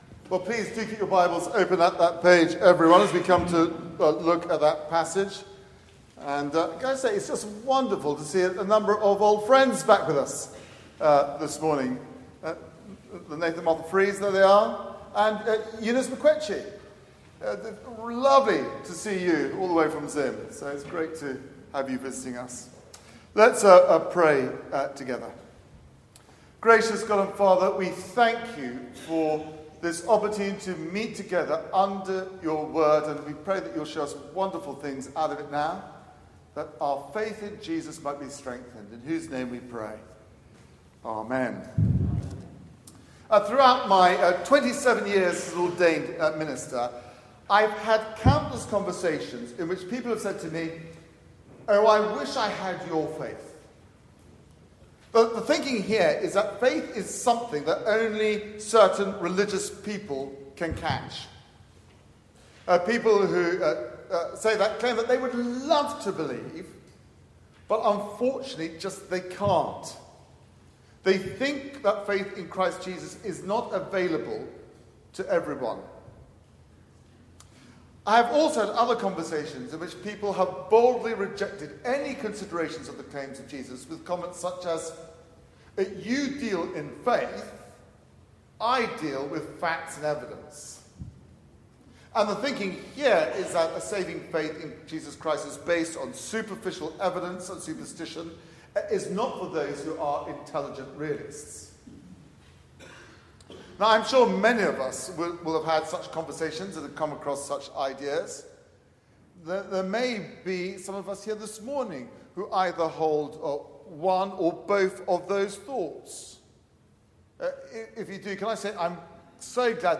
Media for 9:15am Service on Sun 18th Nov 2018
Series: The School of Christ Theme: The yeast of the Pharisees and Saducees Sermon